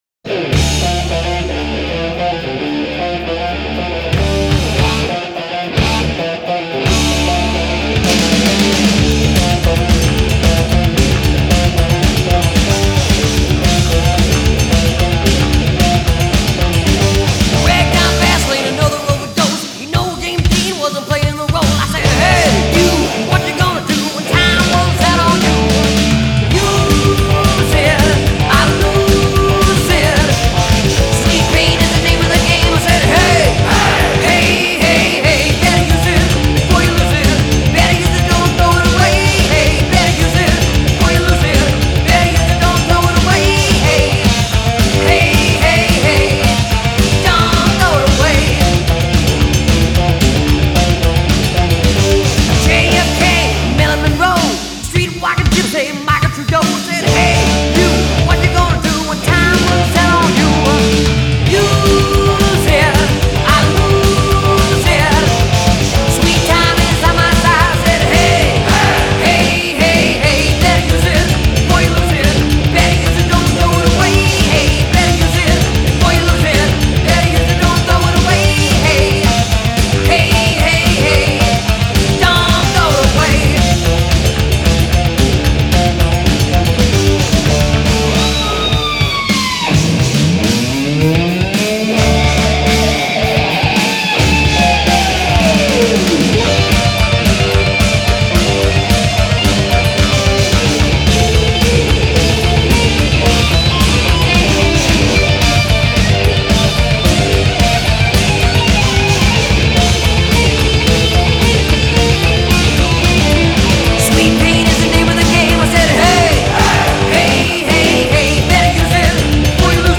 Genre : Rock, Metal